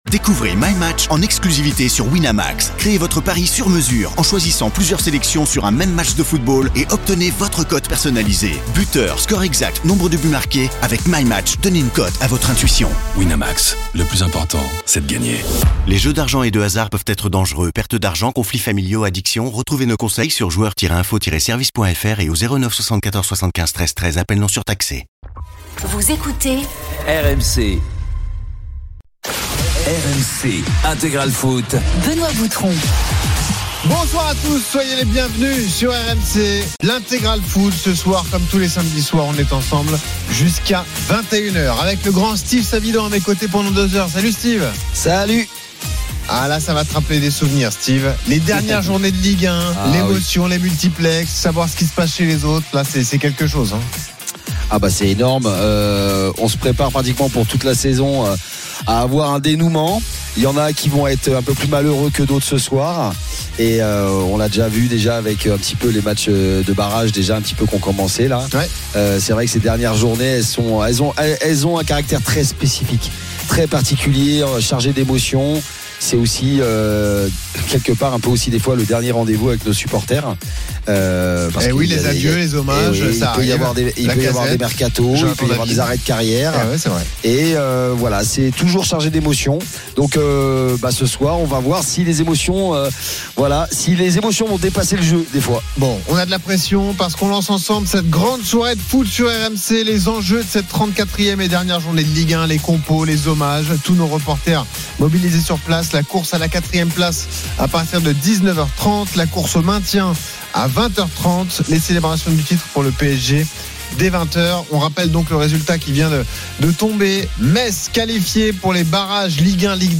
Le rendez vous Ligue 2 de RMC. Huit matches par journée à suivre en direct et des acteurs du championnat (joueurs, entraîneurs, présidents) invités pendant deux heures.
RMC est une radio généraliste, essentiellement axée sur l'actualité et sur l'interactivité avec les auditeurs, dans un format 100% parlé, inédit en France.